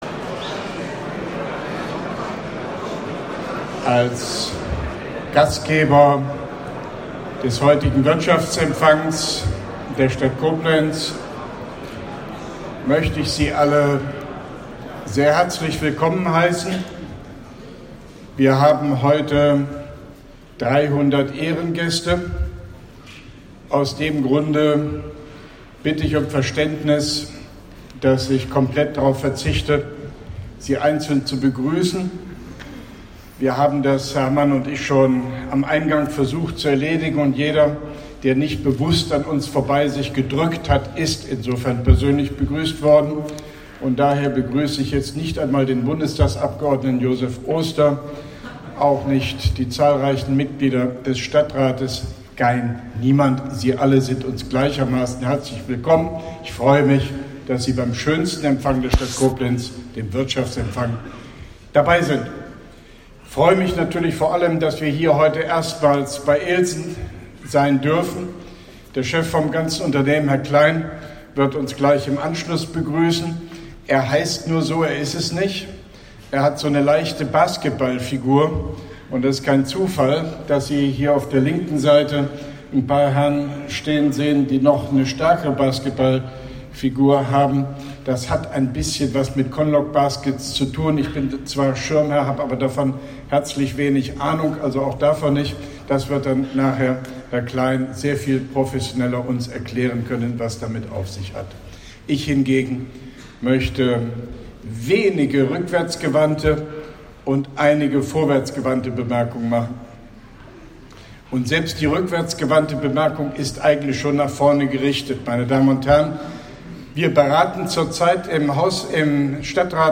Rede von OB Hofmann-Göttig beim Wirtschaftsempfang 2017 der Stadt Koblenz, Koblenz 16.11.2017
Wirtschaftsempfang.mp3